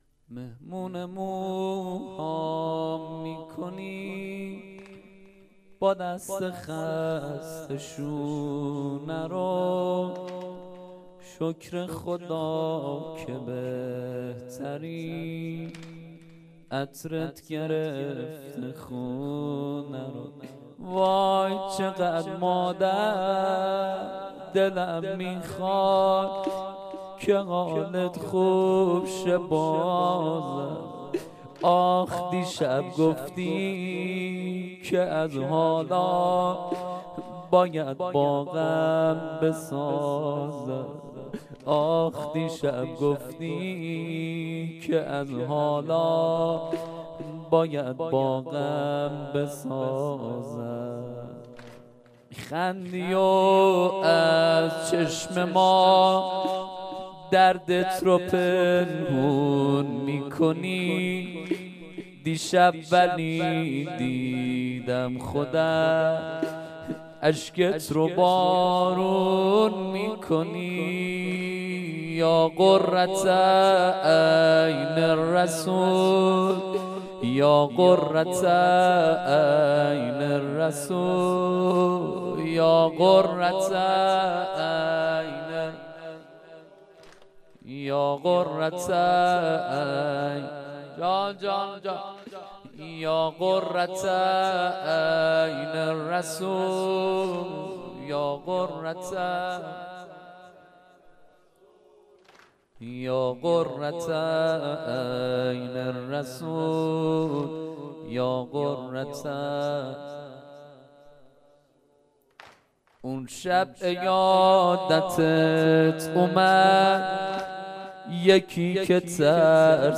نوحه حضرت زهرا